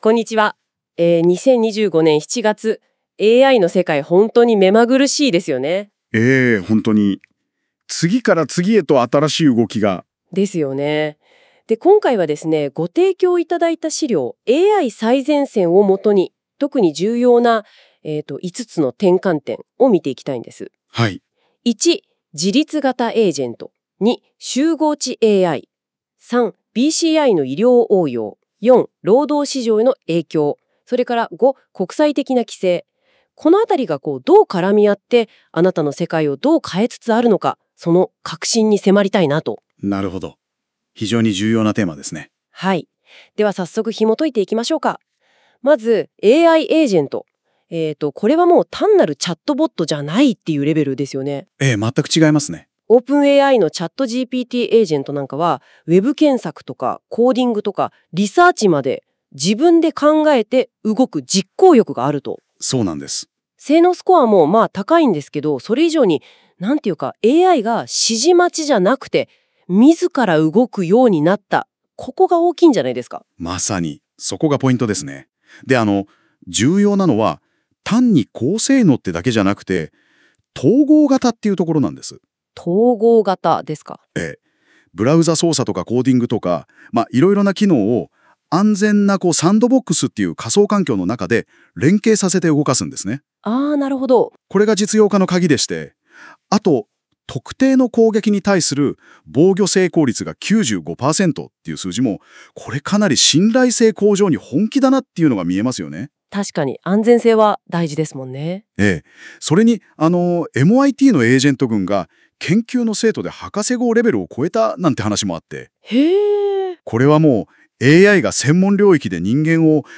忙しい方のために、この記事をポッドキャスト形式で解説しています。
※ この音声は Google NotebookLM を使用して記事内容から生成されています。